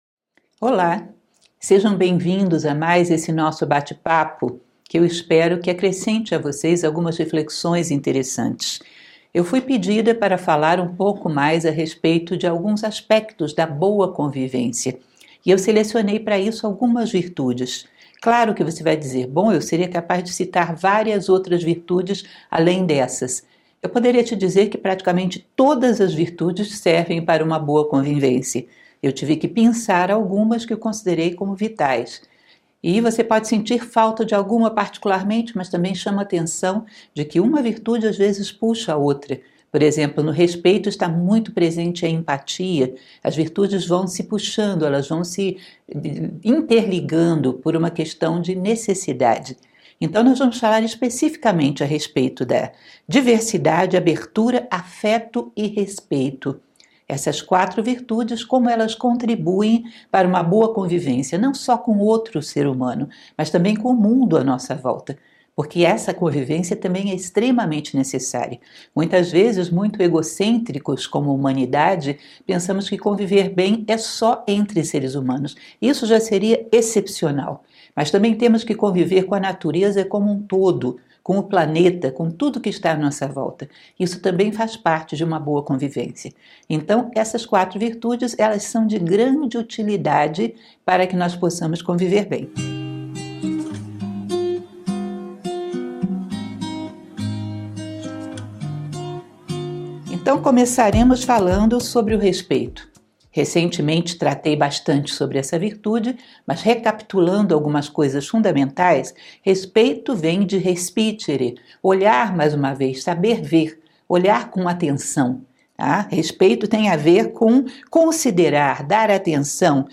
palestra